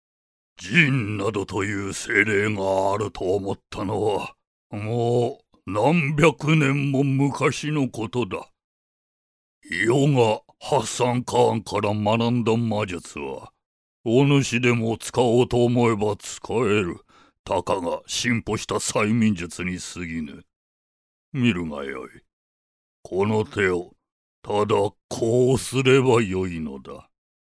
どことなく和を感じさせる低音。
ボイスサンプル5（偉そう） [↓DOWNLOAD]